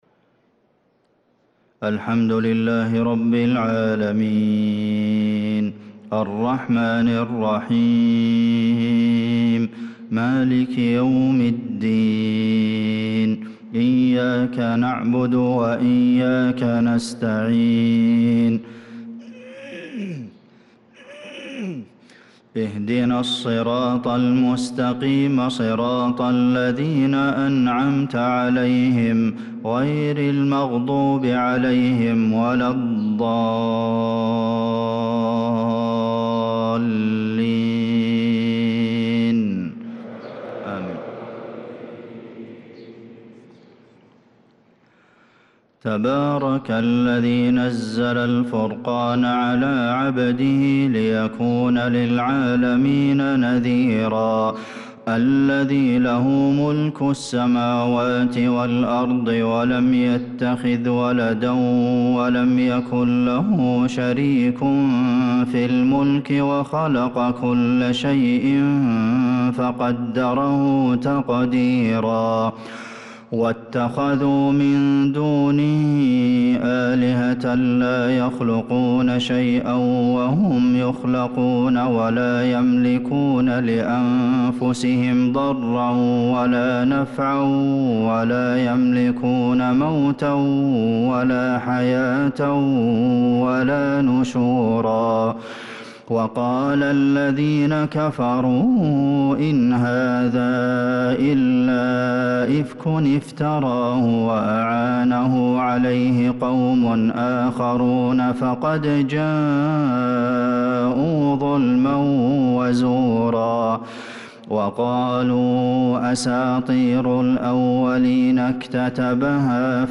صلاة الفجر للقارئ عبدالمحسن القاسم 28 شوال 1445 هـ